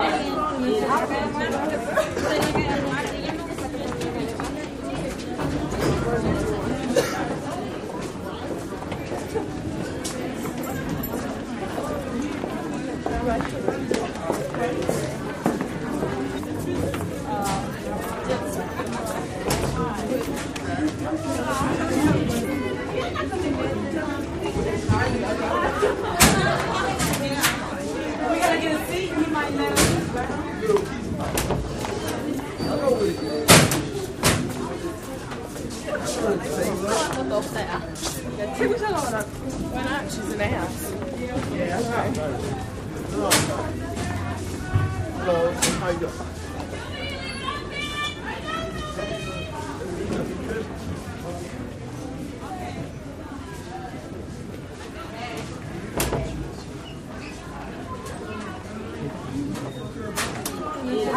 Student Walla
School Crowded Hallway Walla, Lockers